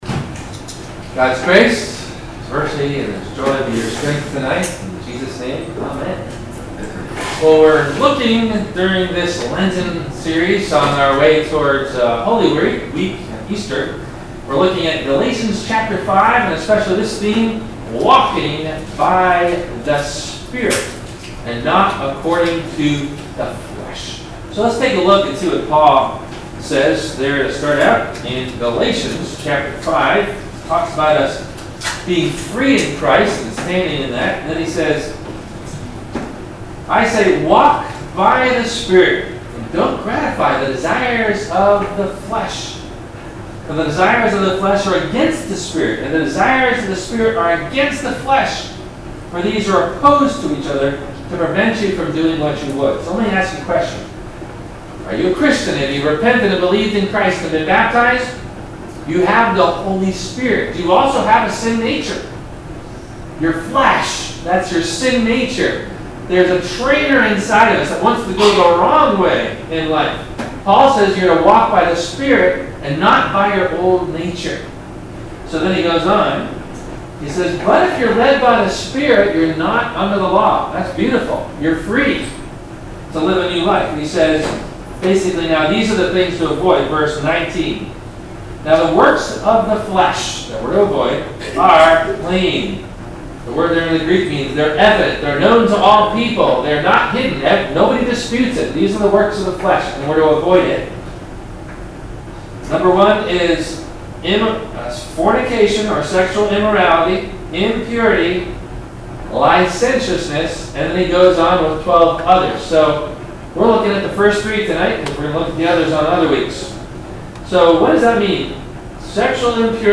Sexual Immorality – Sermon – Wed. Lent 2 – February 21 2018